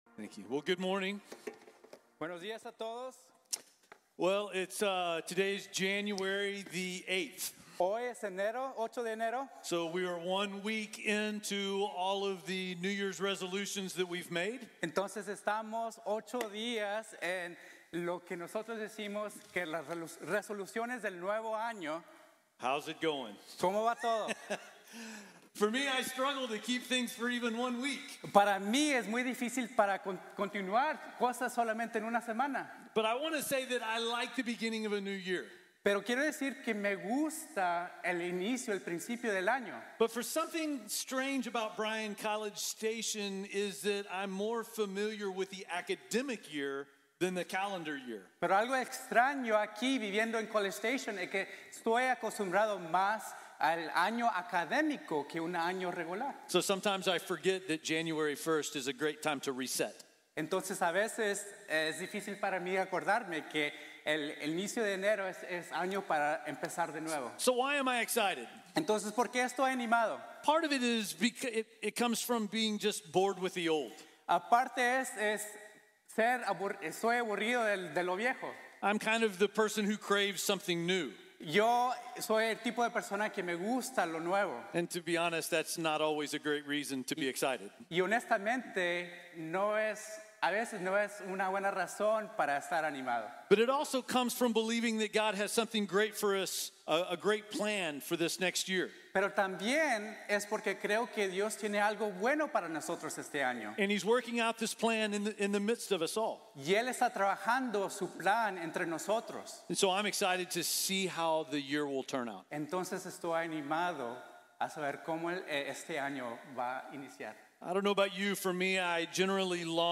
Siete bendiciones espirituales | Sermón | Iglesia Bíblica de la Gracia